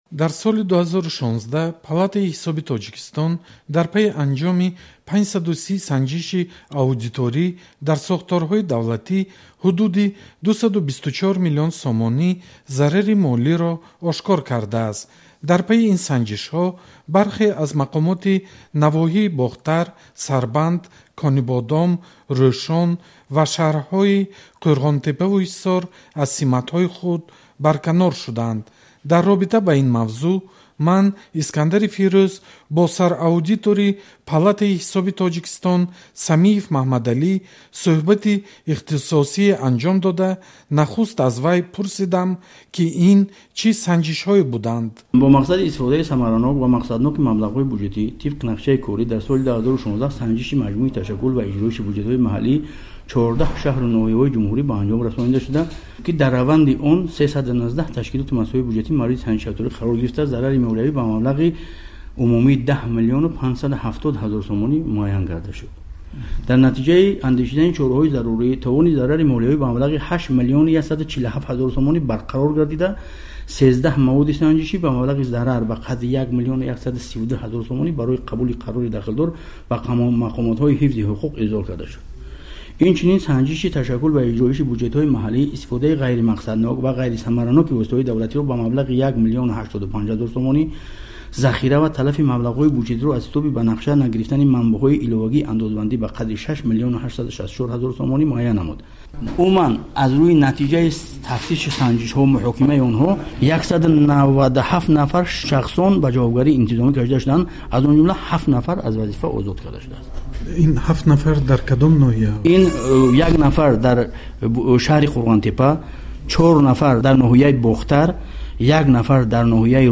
Маҳмадалӣ Самиев, сараудитори Палатаи ҳисоби Тоҷикистон.
Гуфтугӯ бо сараудитори Палатаи ҳисоби Тоҷикистон